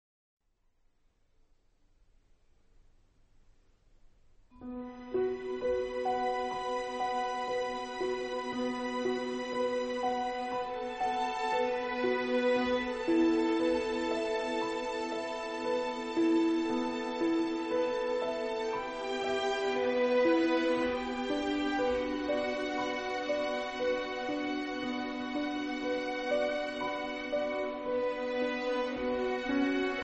• registrazione sonora di musica